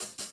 Closed Hats
Headlines Hat3.wav